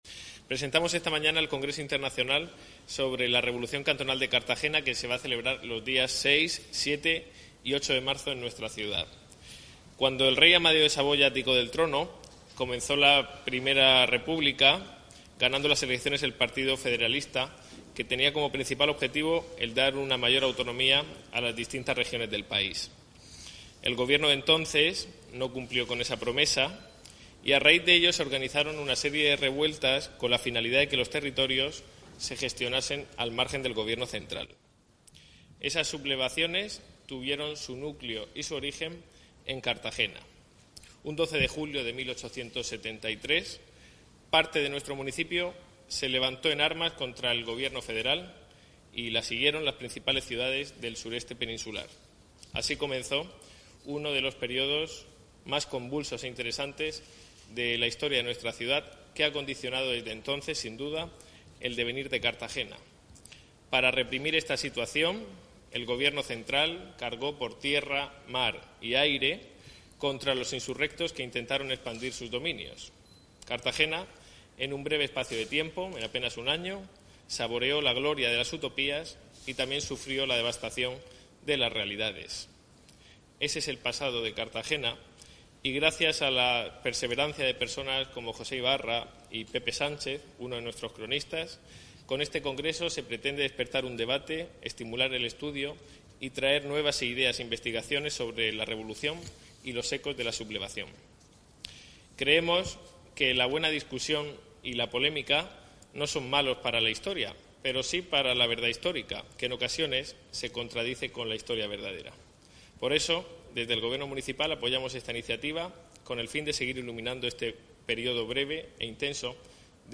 Audio: Declaraciones del edil Ignacio J�udenes
en la presentaci�n del II Congreso Internacional 'La Revoluci�n Cantonal de Cartagena'.